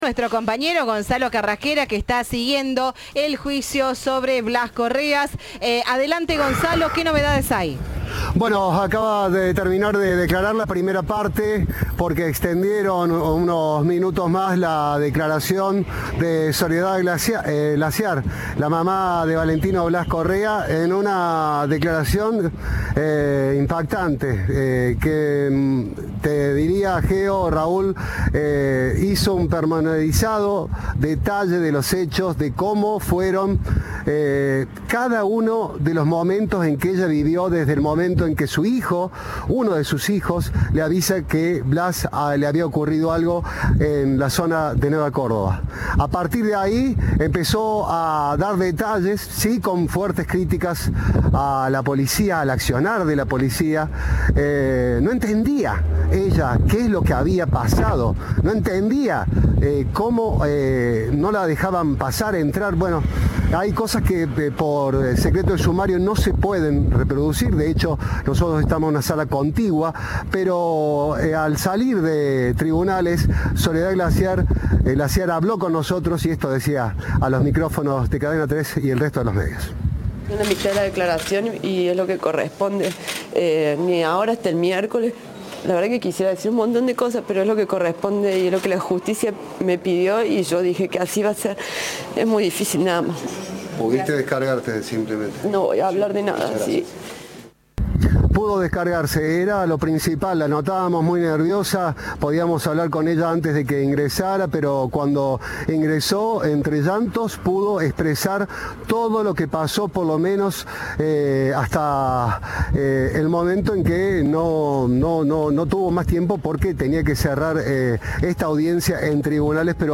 En una breve declaración a la prensa, sollozó entre lágrimas que “es un momento muy difícil”.